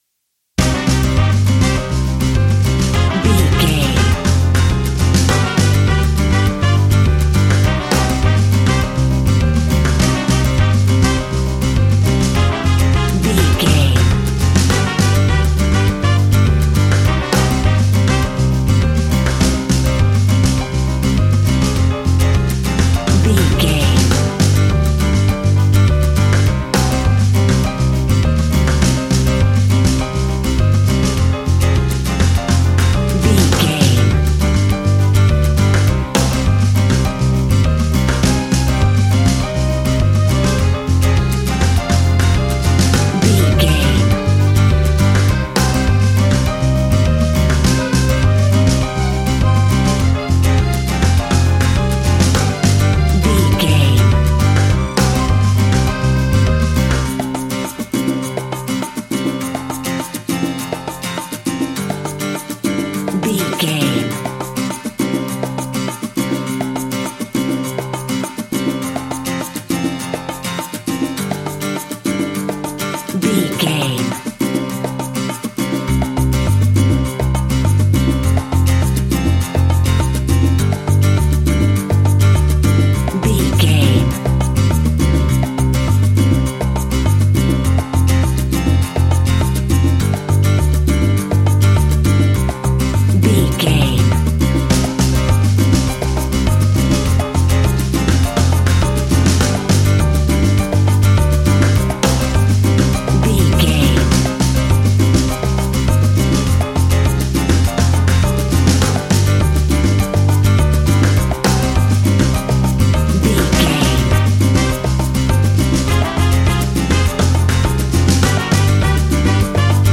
An exotic and colorful piece of Espanic and Latin music.
Ionian/Major
D
flamenco
maracas
percussion spanish guitar
latin guitar